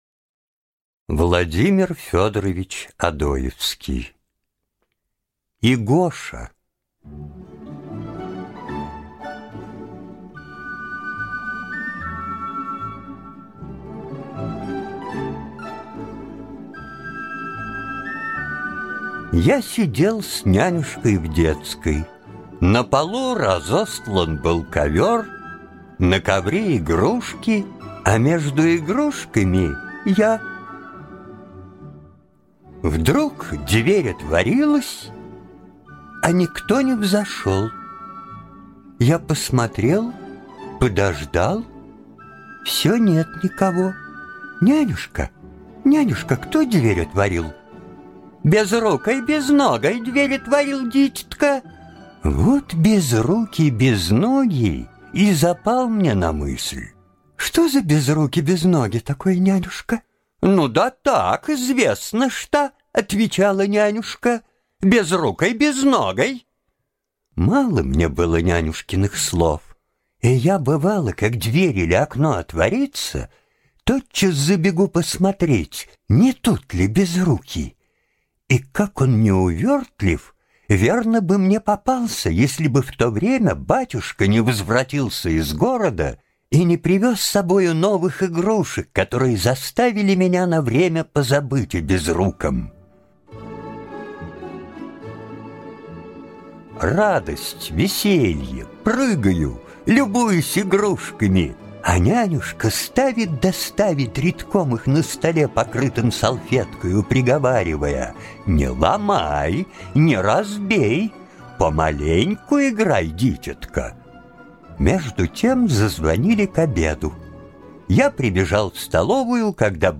Игоша - аудиосказка Владимира Одоевского - слушать онлайн